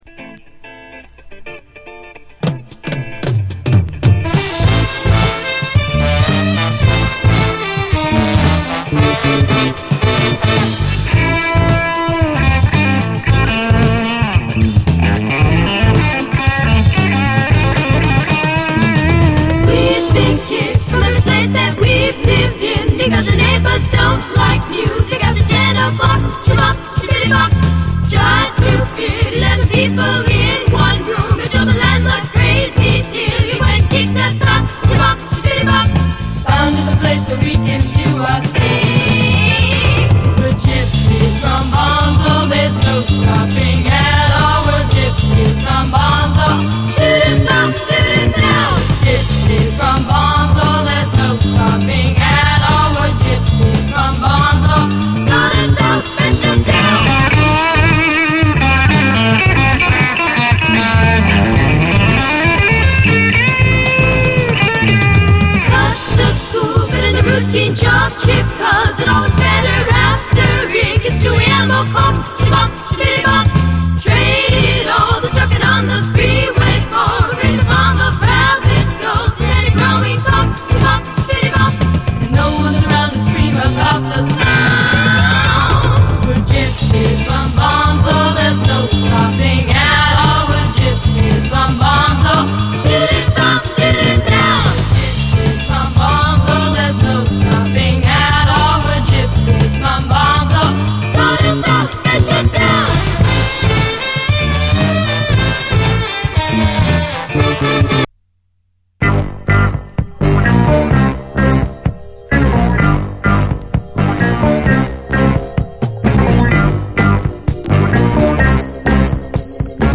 SOUL, 70's～ SOUL, SSW / AOR, ROCK